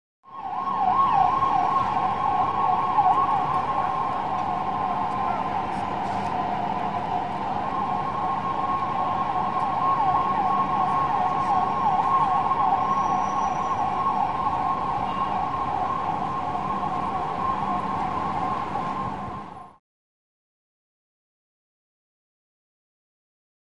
Distant Whooper Siren With Traffic Roar.